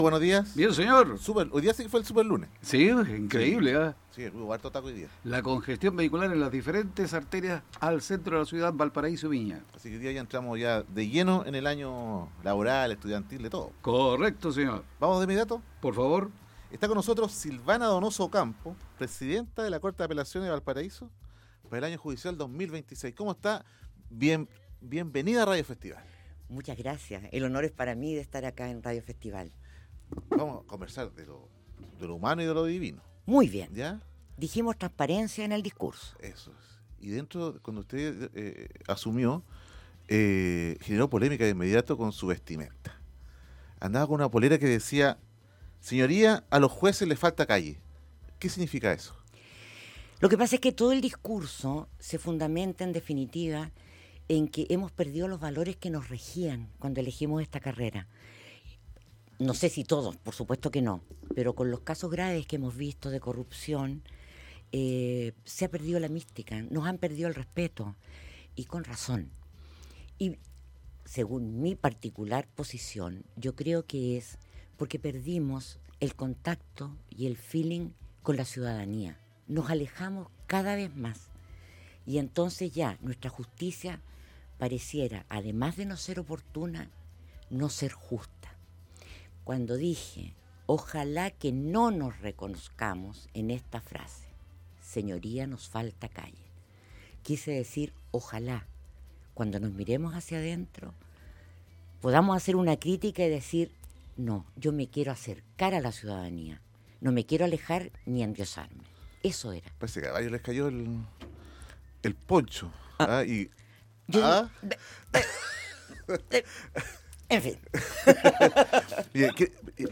La abogada conversó, de lo humano y lo divino, sobre su nuevo cargo en el Poder Judicial. Franca y sin rodeos abordó diferentes temas.